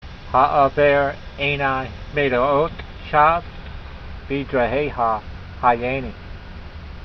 v37_voice.mp3